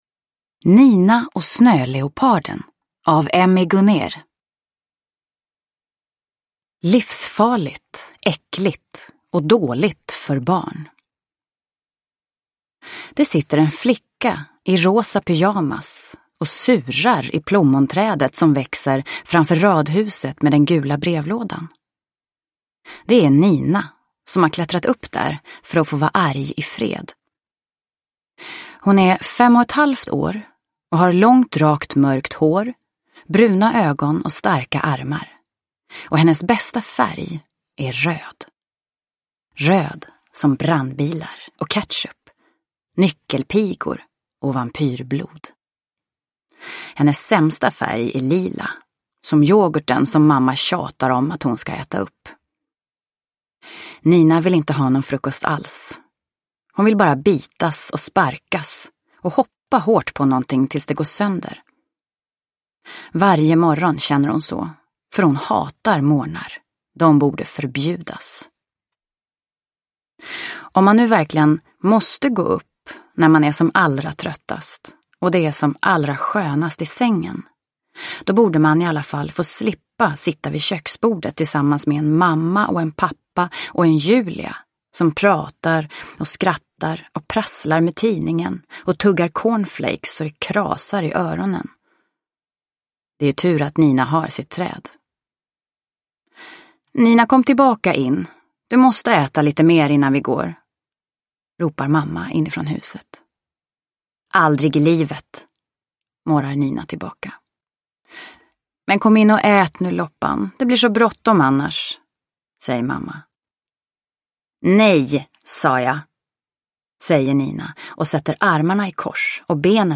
Nina och snöleoparden – Ljudbok – Laddas ner